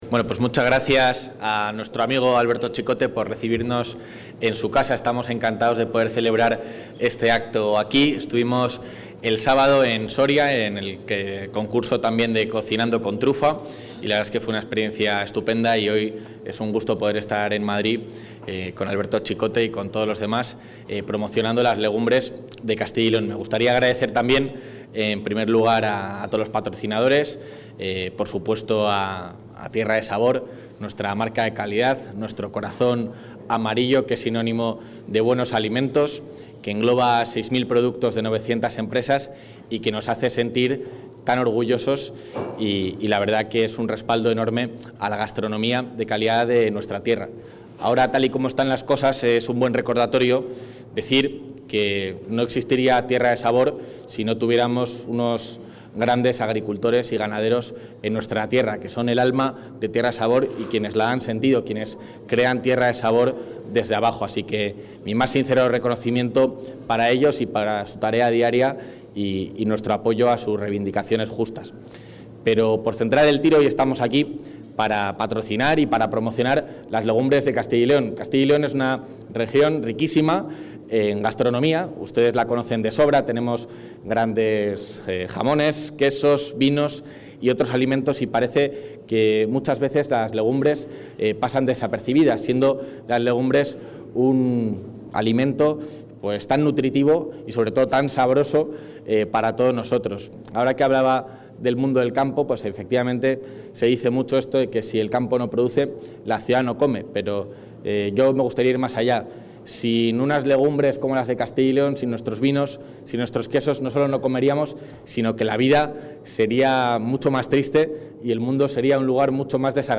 Intervención del vicepresidente de la Junta.